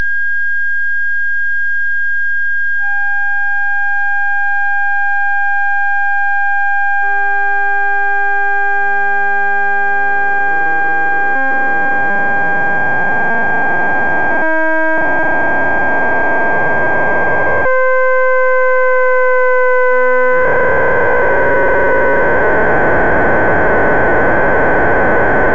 This permits the bifurcations and chaos to be heard with a loudspeaker as the variable resistor (value 1/A) is adjusted from 1000 to 2000 ohms.
A real circuit using parts available for about $10 from Radio Shack has also been constructed and tested.
The digitized signal is actually the integral of x so as to accentuate the low frequencies during the period doublings.  You should be able to hear the period-1, period-2, period-4, and period-8 bifurcations, chaos, the period-6 window, chaos again, the period-5 window with doubling to period-10, and chaos again.